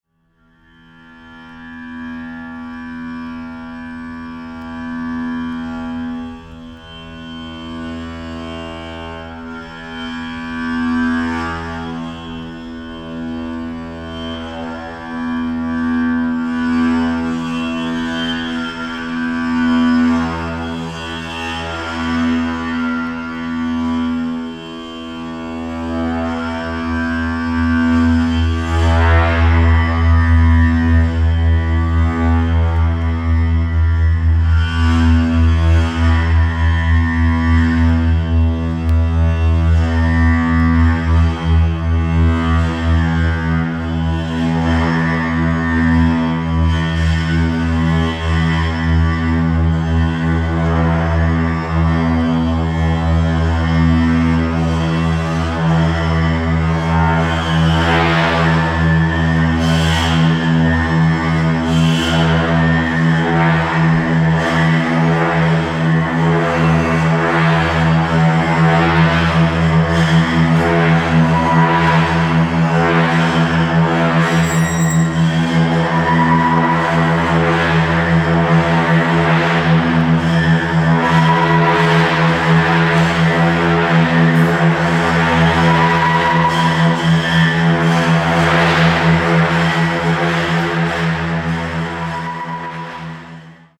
キーワード：ミニマル　創作楽器　空想民俗　Structures Sonores　即興